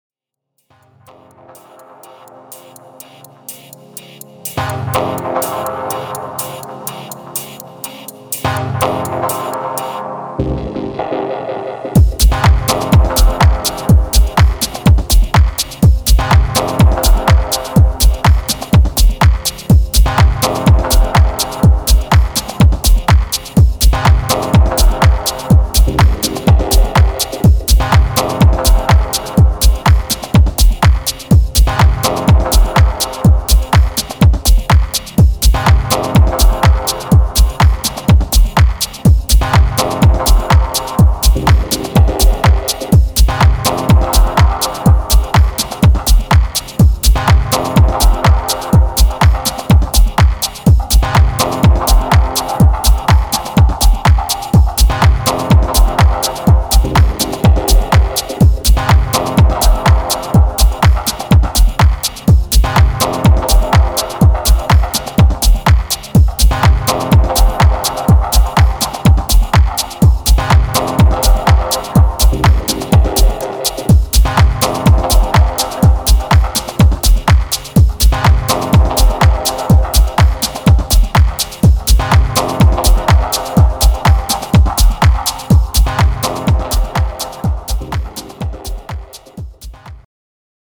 シャッフルしたハットとパーカッションが非常にファンキーなミニマルチューン